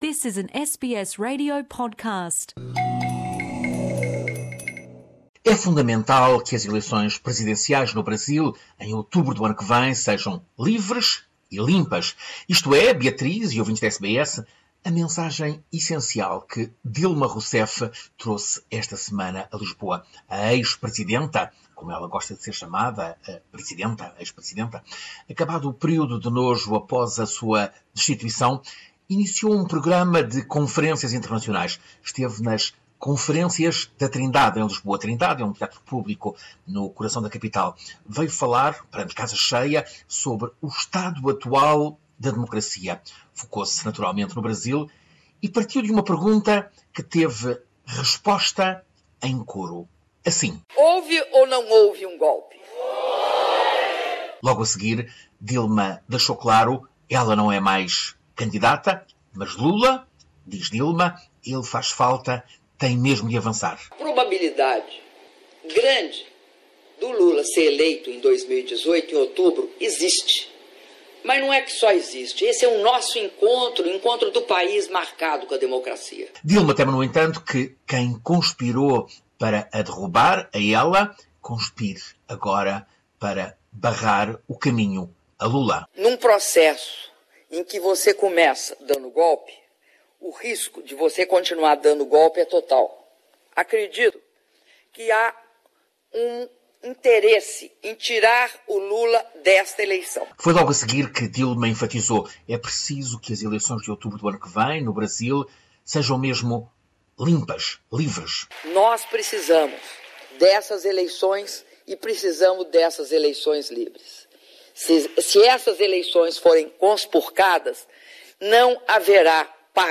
Boletim do correspondente do Programa Português da Rádio SBS em Portugal.